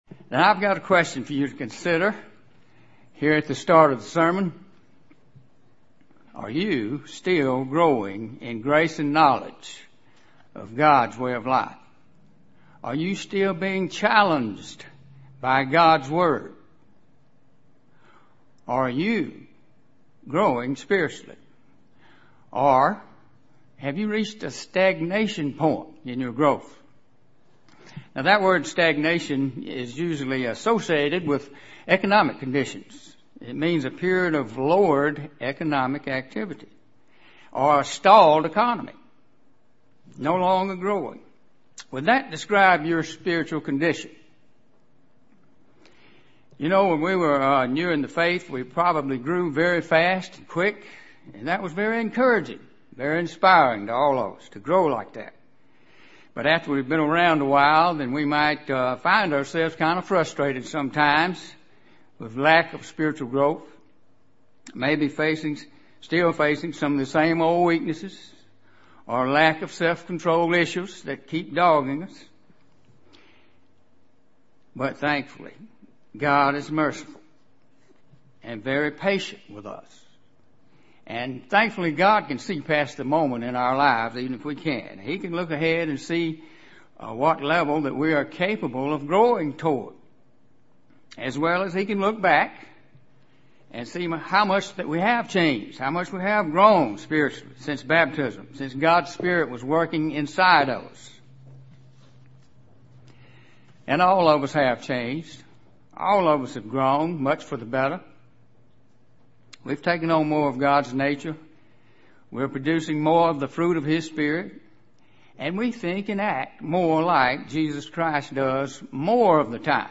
Given in Columbus, GA
UCG Sermon Studying the bible?